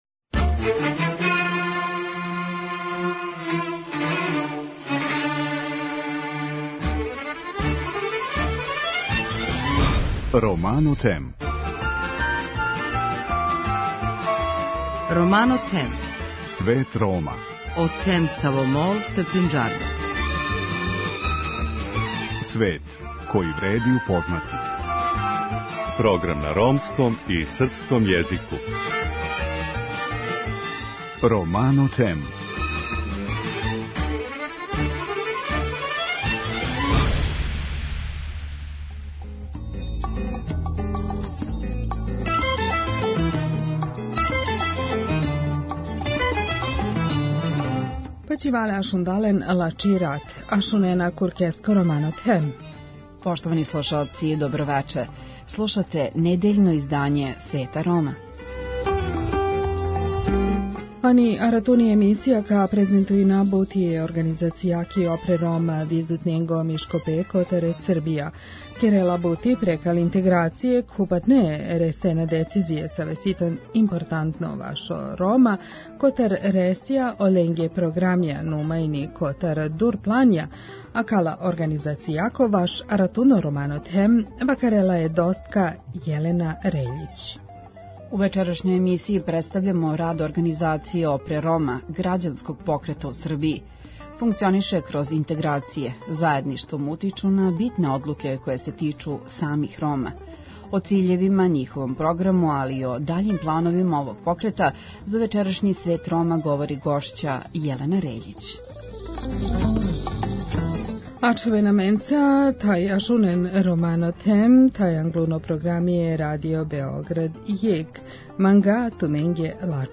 У вечерашњој емисији представљамо рад организације Опре Рома – грађанског покрета у Србији. О циљевима, програму и плановима тог покрета, за вечерашњи Свет Рома говори гошћа